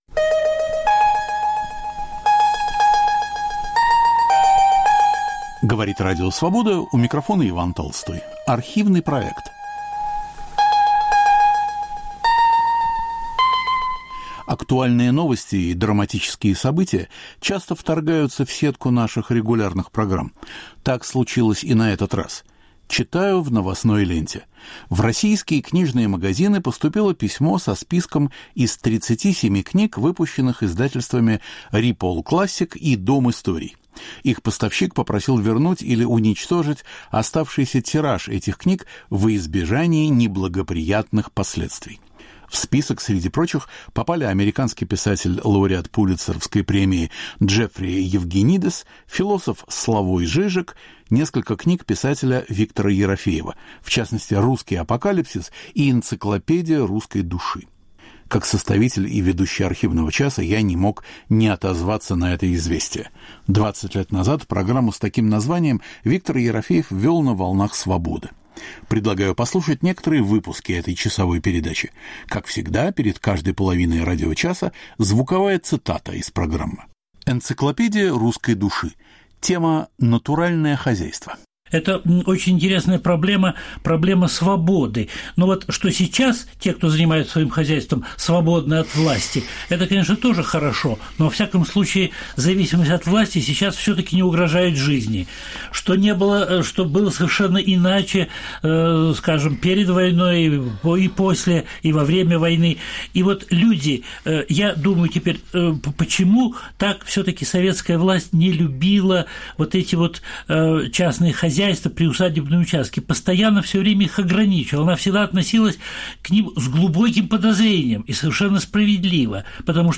Автор и ведущий Виктор Ерофеев. Впервые в эфире 8 мая 2004.